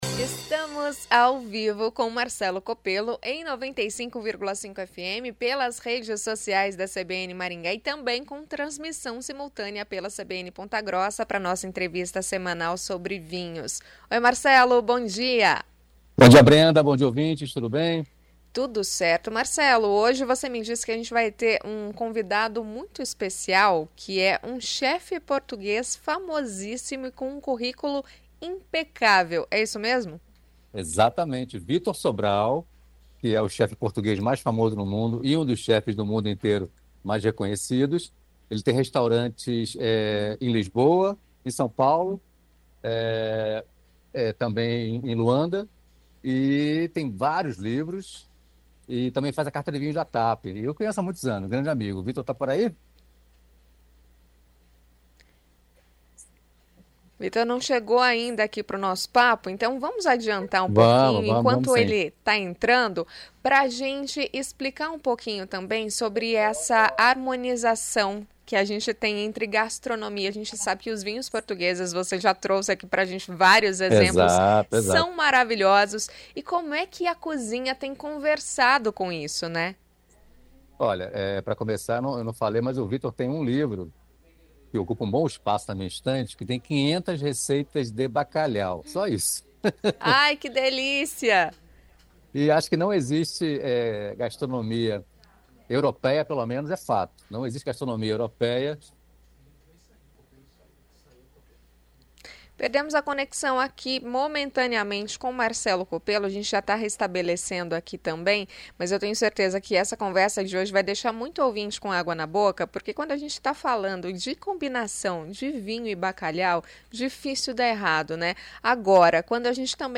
Bacalhau com vinho tinto? Chef explica quando a dupla funciona